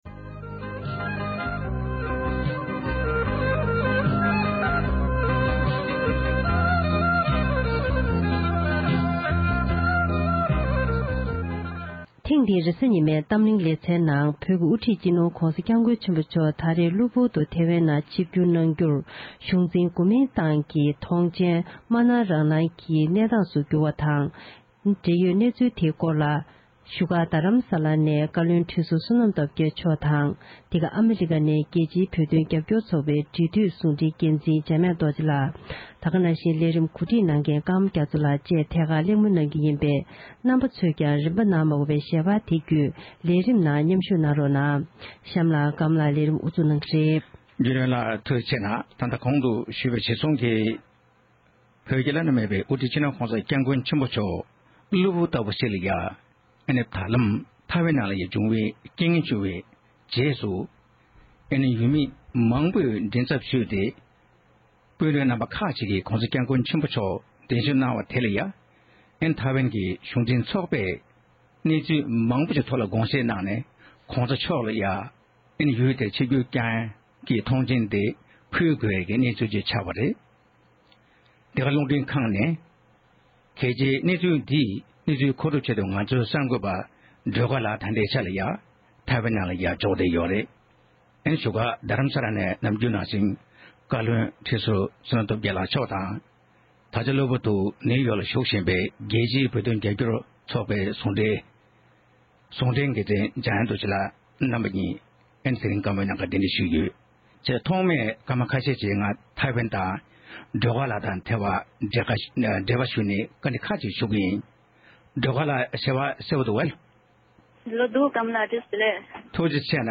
༸གོང་ས་མཆོག་བློ་བུར་དུ་ཐེ་དབན་ནང་ཆིབས་བསྒྱུར་བསྐྱང་རྒྱུར་གཞུང་འཛིན་ཚོགས་པས་ཆོག་མཆན་ཕུལ་ཡོད་པའི་གནས་ཚུལ་ཐོག་གླེང་མོལ།